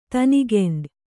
♪ tanigeṇḍa